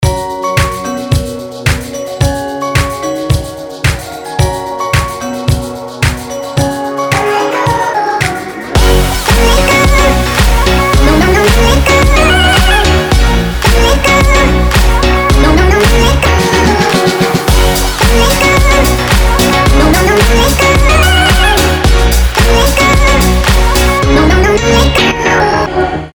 • Качество: 320, Stereo
dance
EDM
future house
club